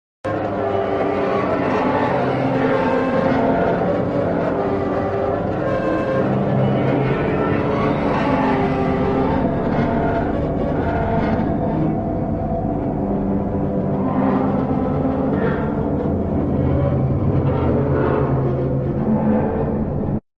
The pandemonium searching meme sound effect is widely used in Instagram Reels, YouTube Shorts, gaming videos, and funny meme edits.